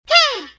yoshi_Hoo_Take_2.ogg